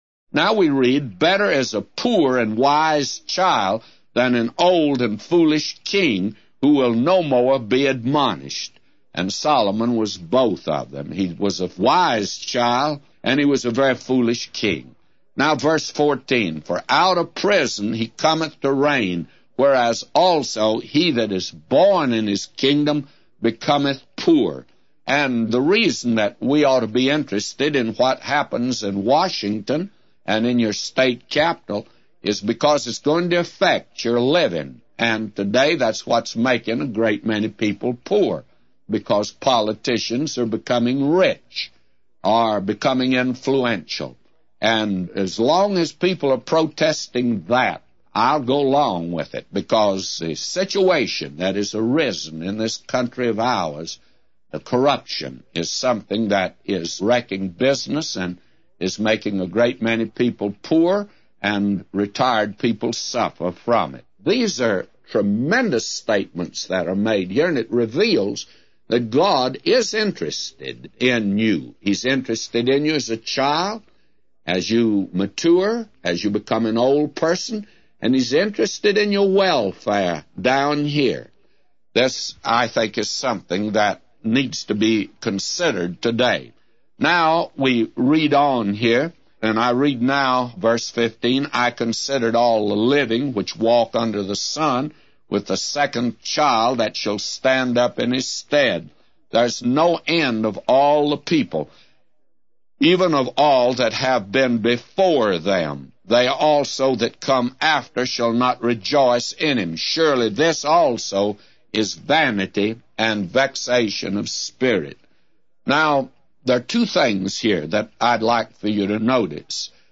A Commentary By J Vernon MCgee For Ecclesiastes 4:13-999